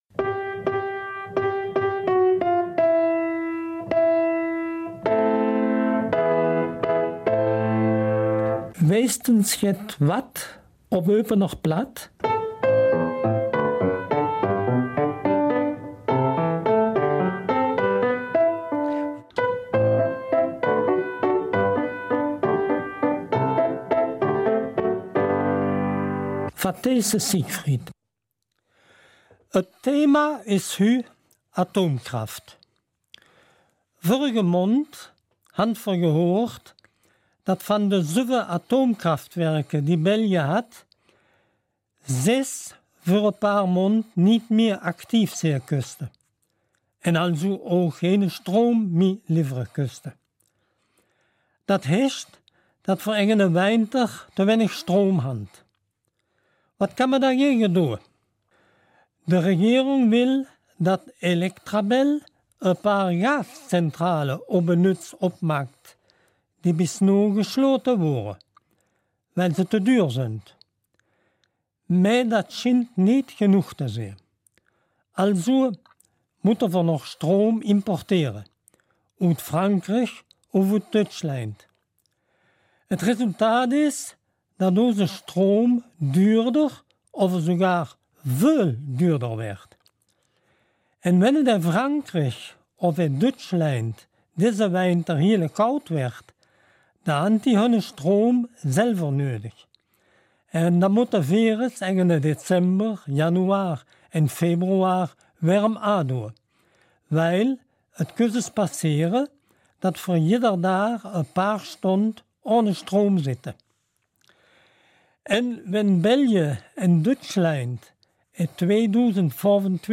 Eupener Mundartsendung zum Thema Atomkraft
October 2018 Eupener Mundart Dein Browser unterstützt kein Audio-Element.